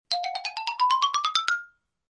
Descarga de Sonidos mp3 Gratis: xilofono 27.